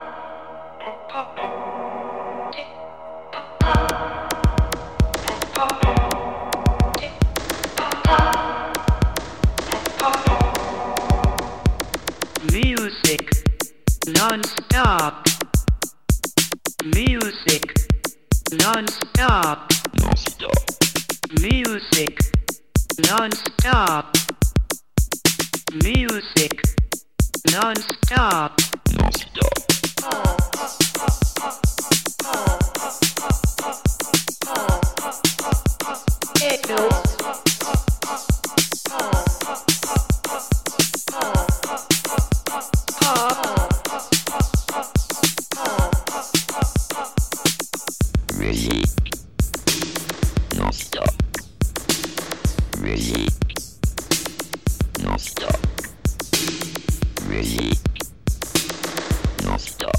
Электронная